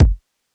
Kicks
DJP_KICK_ (114).wav